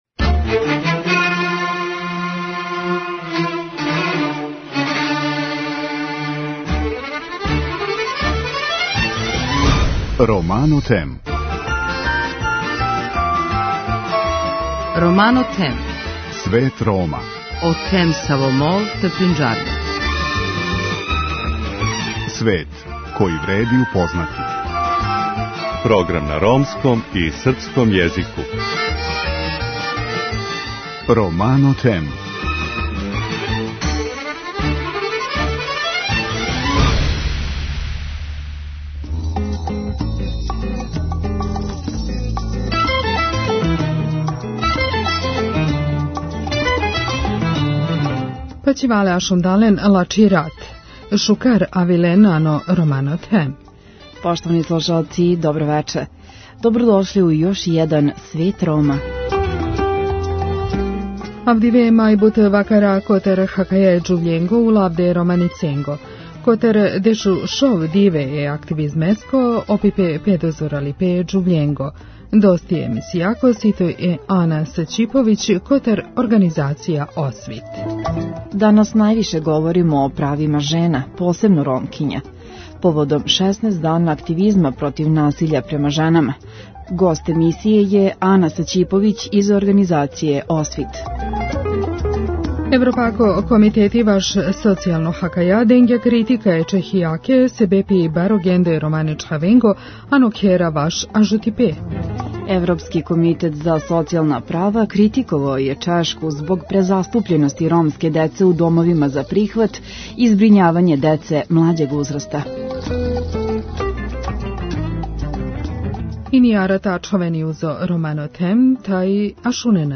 Данас највише говоримо о правима жена, посебно Ромкиња. Повод је акција-16 дана активизма против насиља над женама. Гошћа емисије